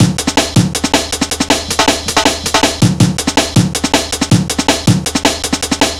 Index of /90_sSampleCDs/Zero-G - Total Drum Bass/Drumloops - 1/track 07 (160bpm)